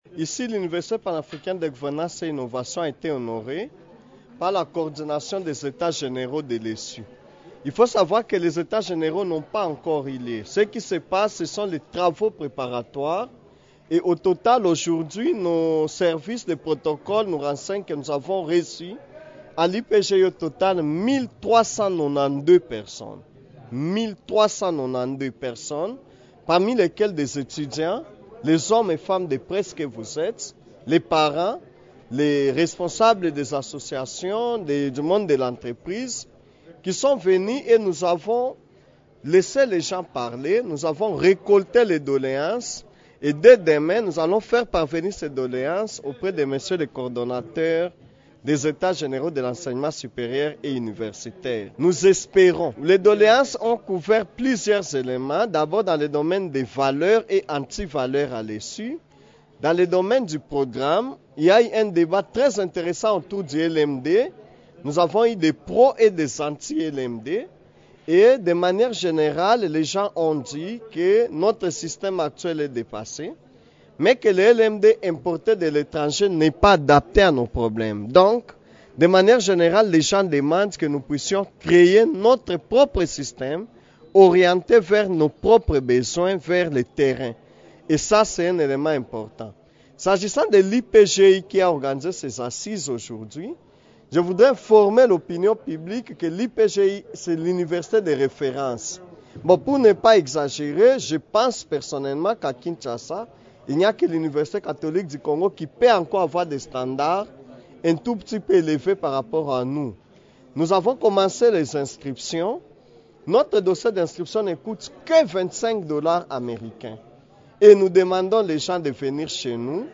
Le point sur les grandes lignes de ces travaux préparatoires dans cet entretien